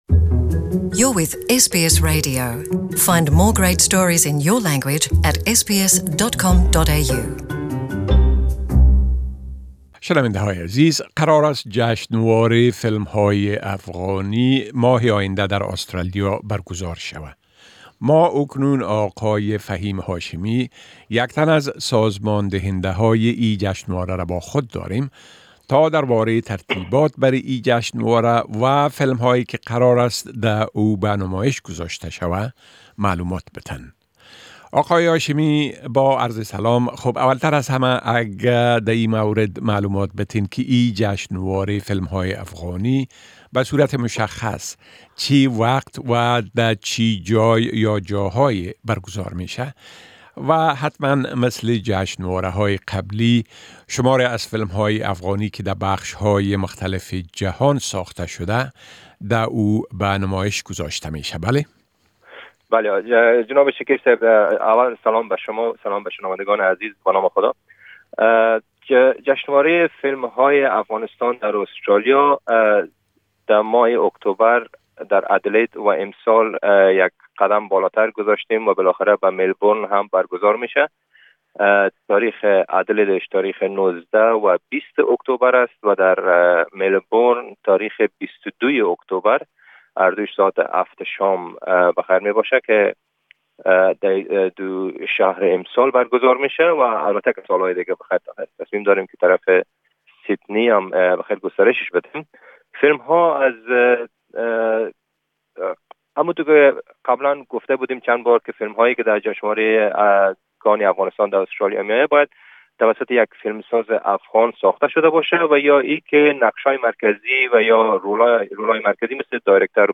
The Afghan films festival is due to be held late October in Adelaide and Melbourne. An interview